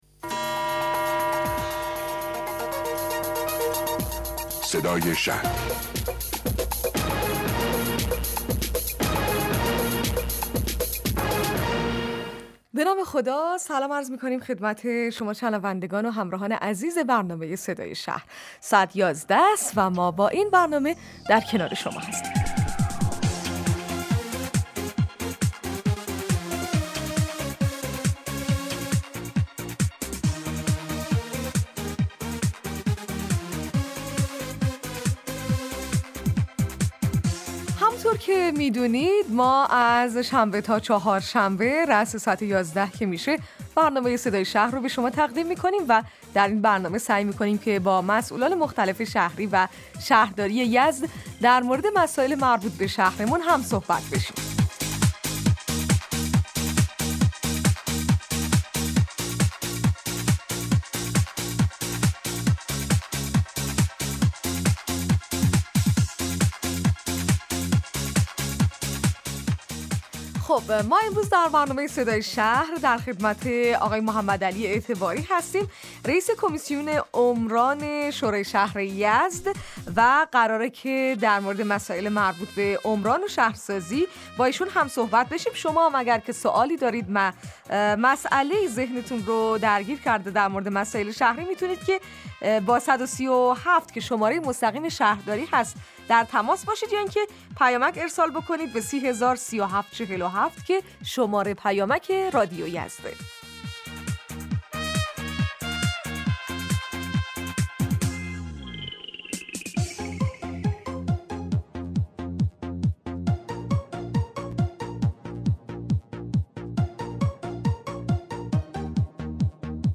مصاحبه رادیویی برنامه صدای شهر با حضور محمدعلی اعتباری رئیس کمیسیون شهرسازی، معماری و عمران شورای اسلامی شهر یزد